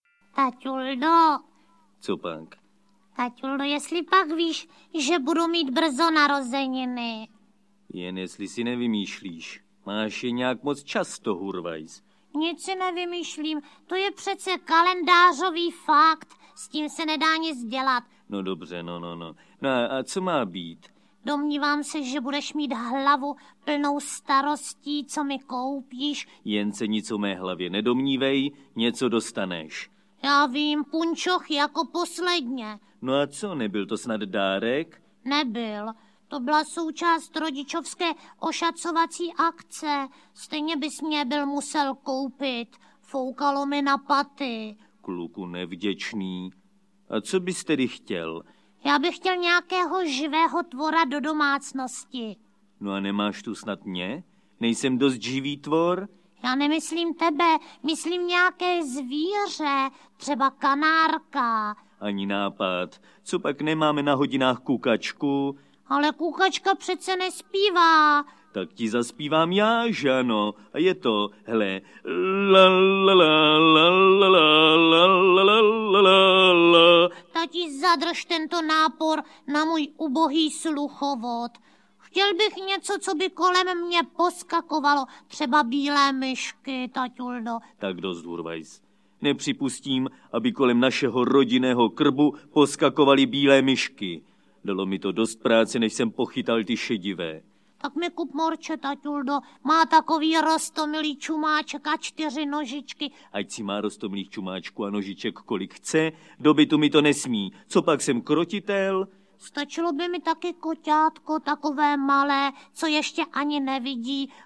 Jak Hurvínek do lesa volá, tak se Spejbl ozývá audiokniha
Audio kniha
Ukázka z knihy